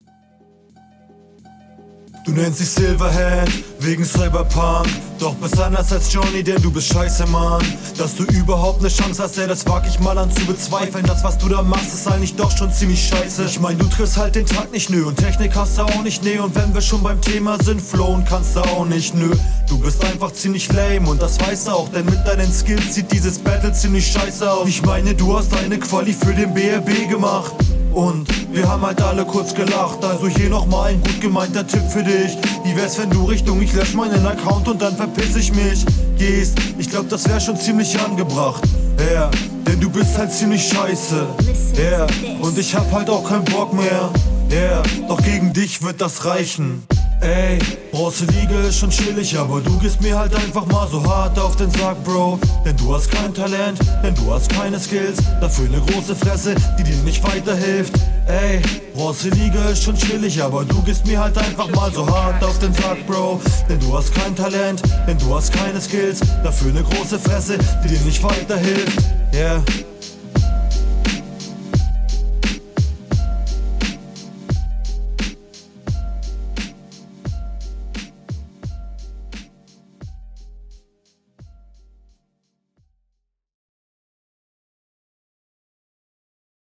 Flow ist aber cool.